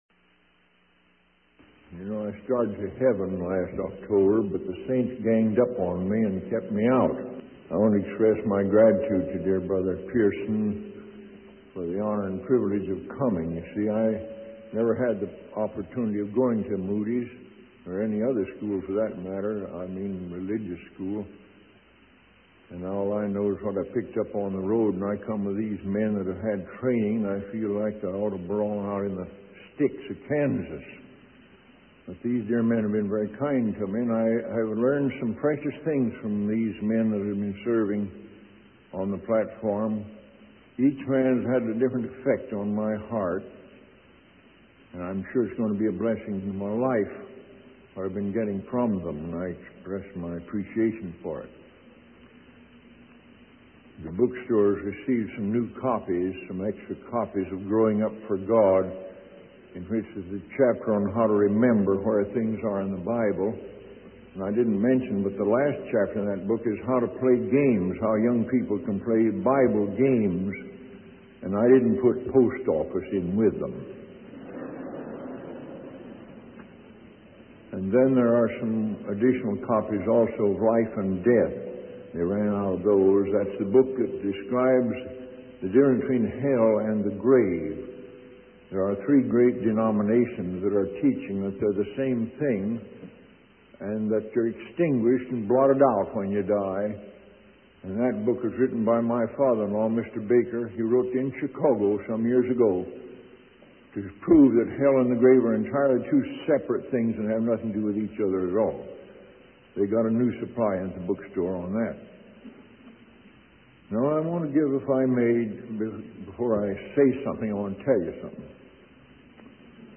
In this sermon, the speaker emphasizes the importance of knowing God's ways and seeking His presence in our lives.